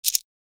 جلوه های صوتی
دانلود آهنگ موس 21 از افکت صوتی اشیاء
برچسب: دانلود آهنگ های افکت صوتی اشیاء دانلود آلبوم صدای کلیک موس از افکت صوتی اشیاء